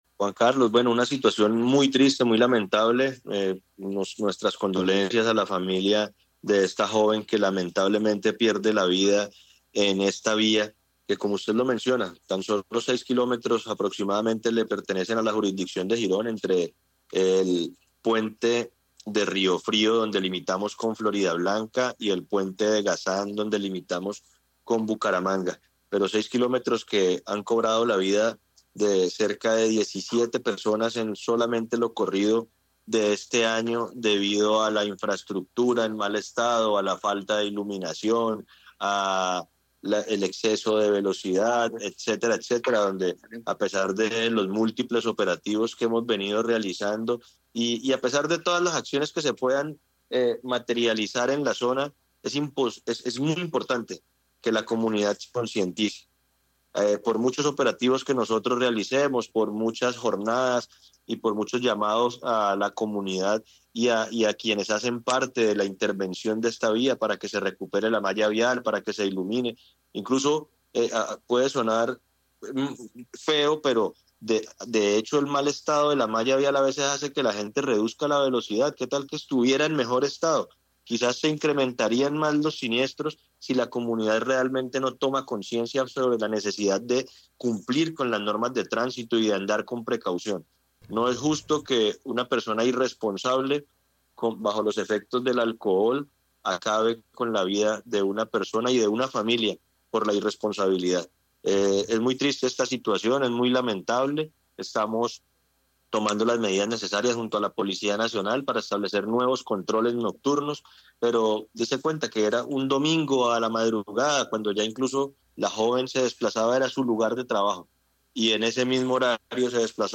Juan José Gómez, secretario de Tránsito de Girón
Así lo confirmó en entrevista con Caracol Radio Juan José Gómez, secretario de Tránsito de Girón, quien detalló que de las 26 muertes registradas en todo el municipio este año el 65% han ocurrido en ese pequeño pero letal tramo del Anillo Vial.